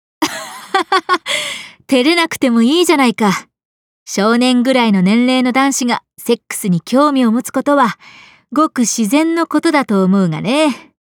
cha10kazumi_voice_sample.mp3